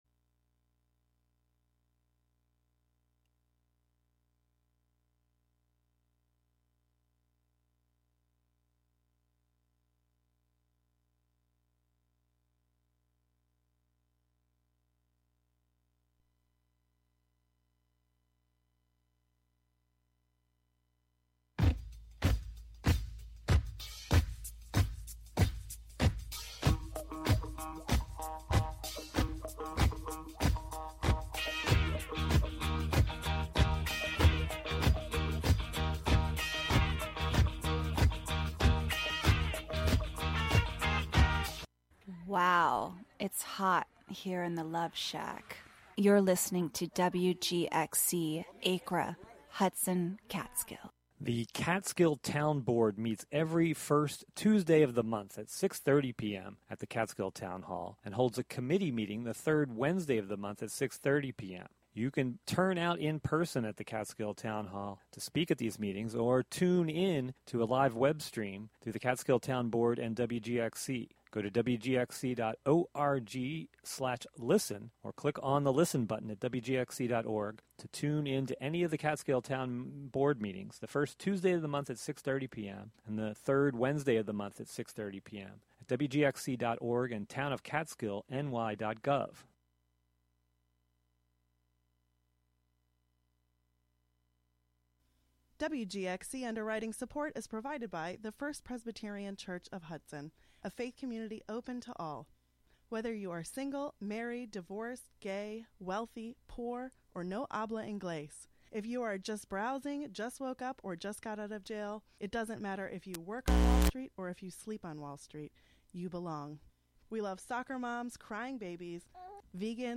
The Love Motel is a monthly late-night radio romance talk show with love songs, relationship advice, and personals for all the lovers in the upper Hudson Valley.